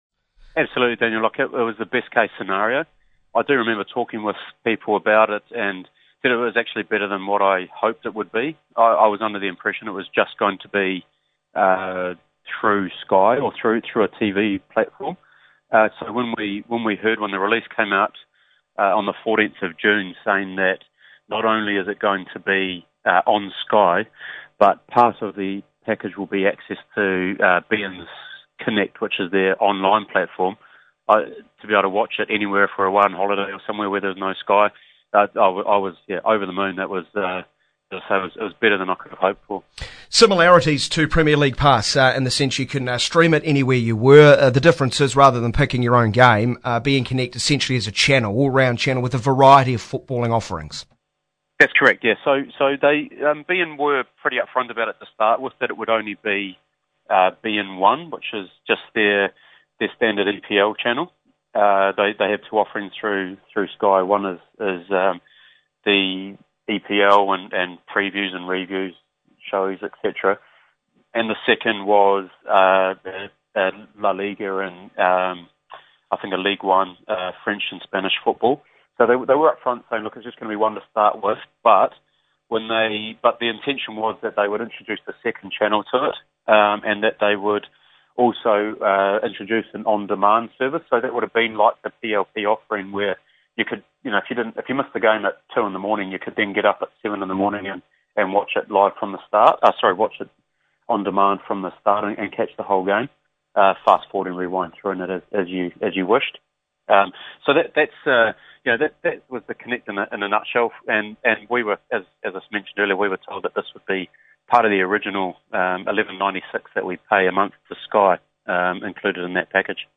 on Radio Sport